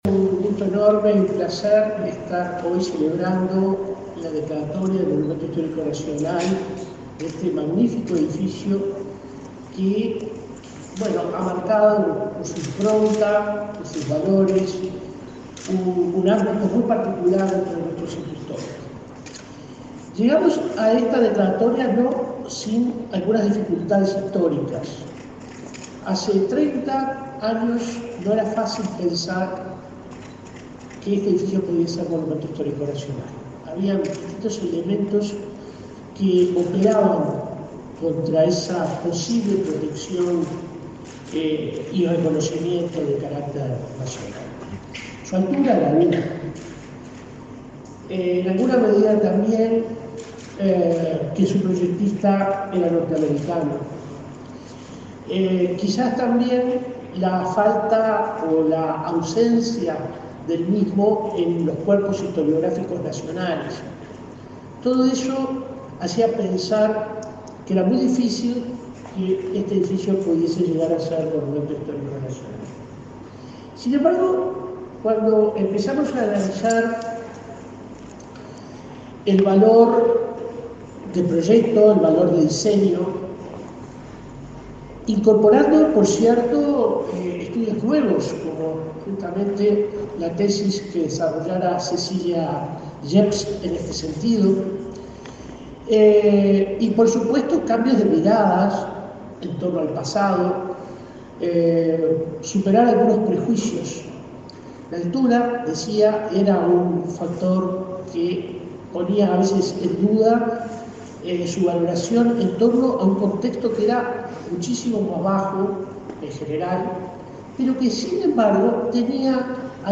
Palabras de autoridades de la Educación
Palabras de autoridades de la Educación 03/06/2022 Compartir Facebook X Copiar enlace WhatsApp LinkedIn El Ministerio de Educación y Cultura, a través de la Comisión del Patrimonio Cultural de la Nación, declaró Monumento Histórico Nacional al edificio Artigas, ubicado en la intersección de las calles Rincón y Treinta y Tres, en el barrio Ciudad Vieja, de Montevideo. El responsable de la Comisión de Patrimonio, William Rey, y el ministro Pablo da Silveira destacaron el valor del inmueble.